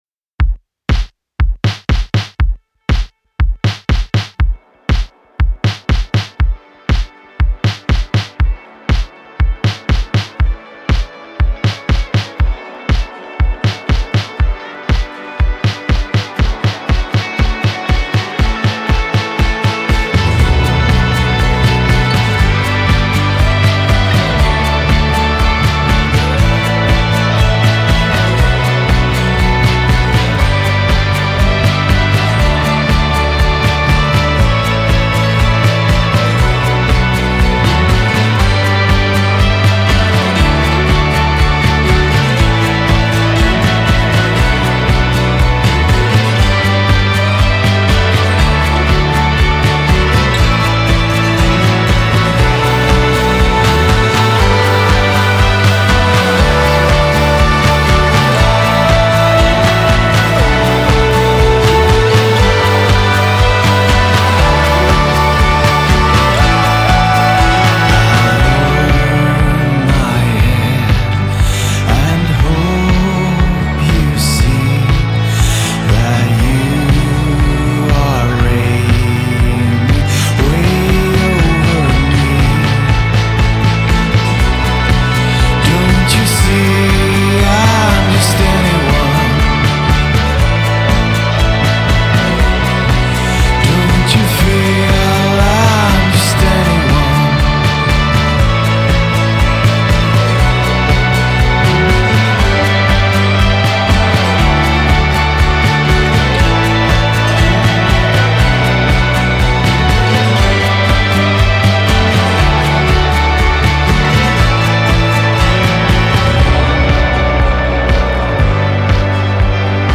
pop corale